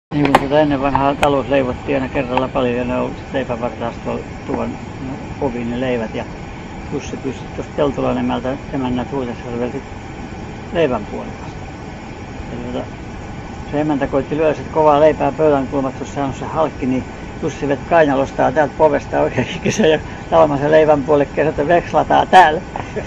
kertomusta